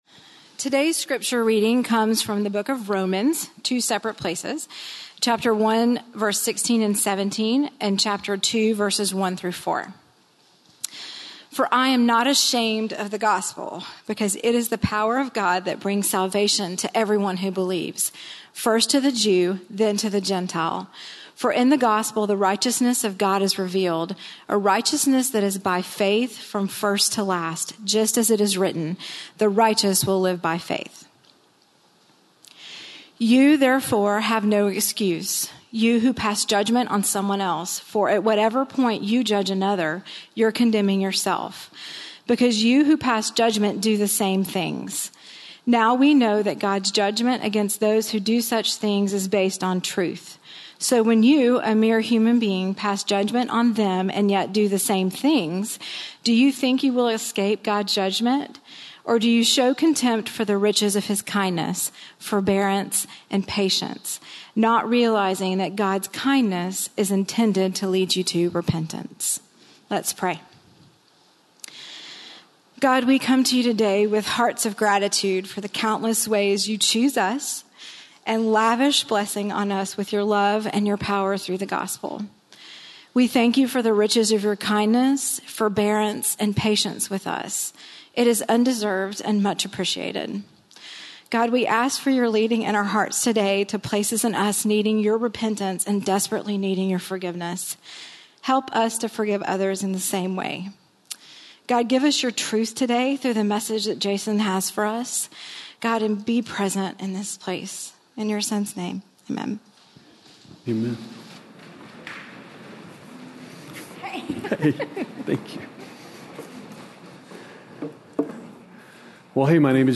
Finding Our Way with Sexuality - Sermon - Lockeland Springs